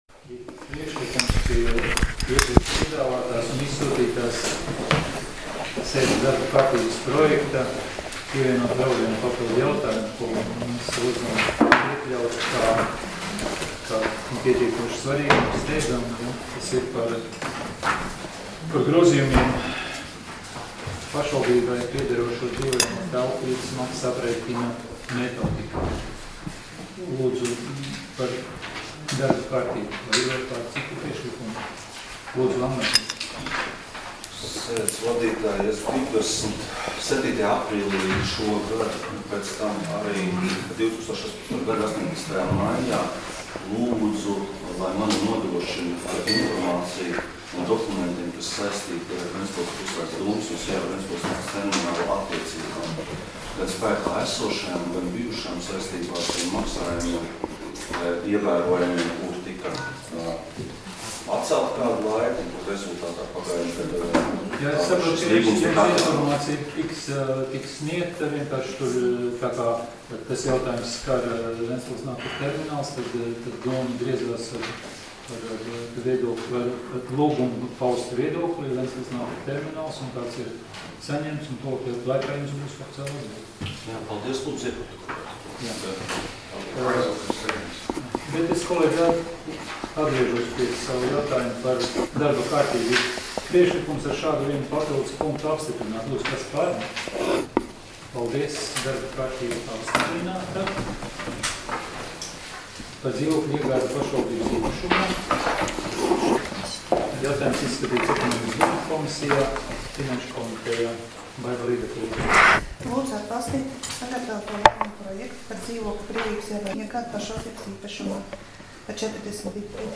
Domes sēdes 29.06.2018. audioieraksts